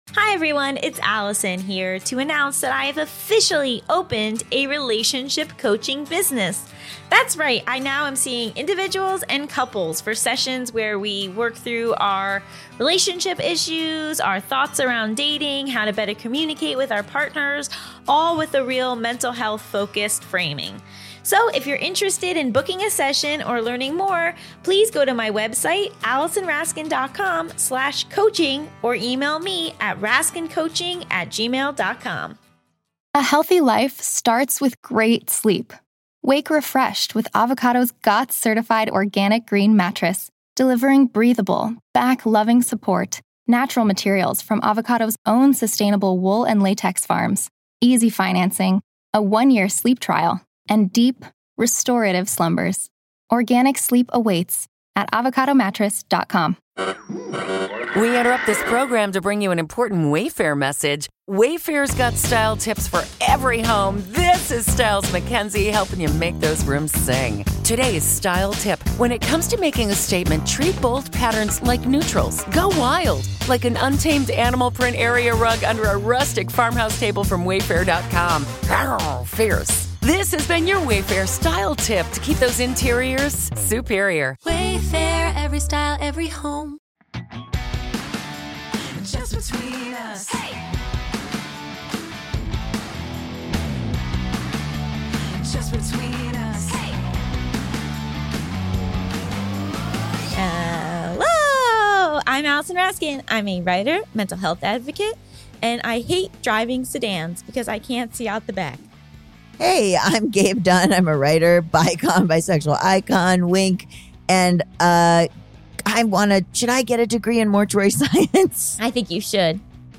Mental Health, Education, Sexuality, Comedy, Self-improvement, Relationships, Comedy Interviews, Society & Culture, Personal Journals, Health & Fitness